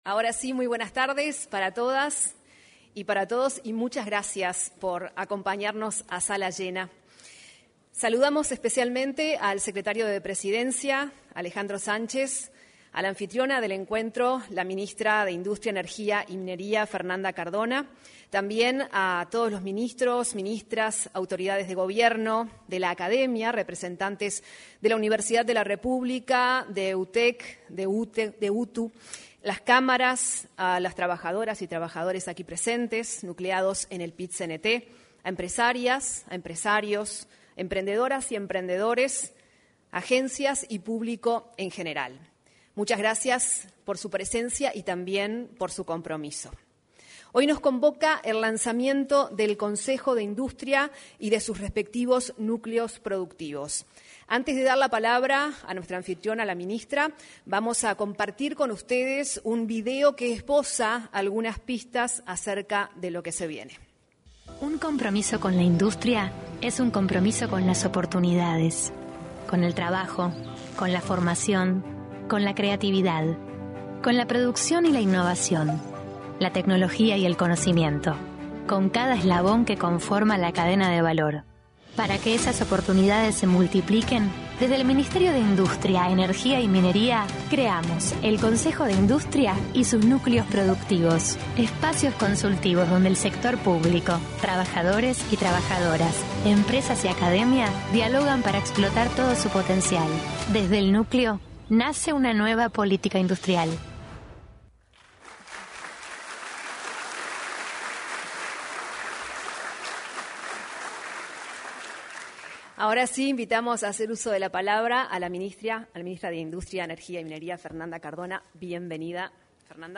Lanzamiento del Consejo de Industria y los Núcleos Productivos 29/07/2025 Compartir Facebook X Copiar enlace WhatsApp LinkedIn El Ministerio de Industria, Energía y Minería celebró el lanzamiento del Consejo de Industria y los Núcleos Productivos, iniciativas para impulsar espacios de intercambio y construir políticas sobre la temática. En el encuentro, participaron la titular de la citada cartera, Fernanda Cardona, y el director de Industrias, Adrián Míguez.